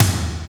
Index of /90_sSampleCDs/Roland L-CDX-01/TOM_Rolls & FX/TOM_Tomish FX
TOM FAT T03R.wav